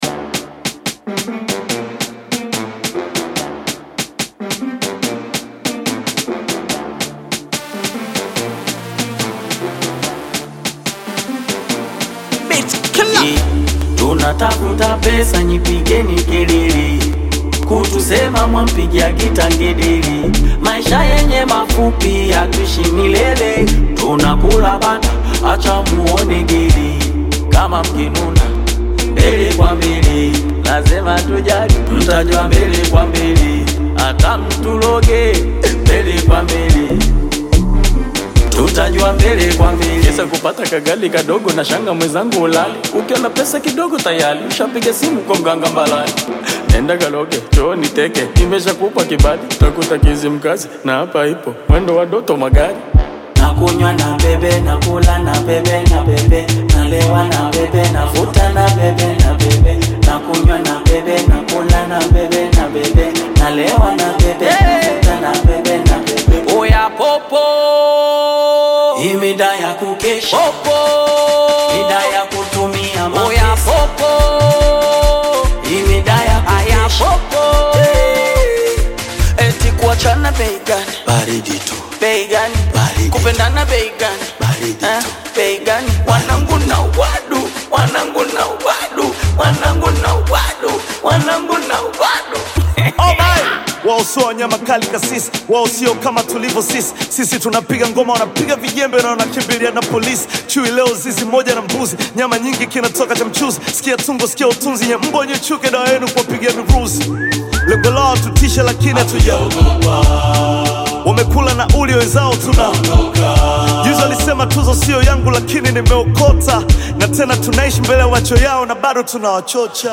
AudioBongo flava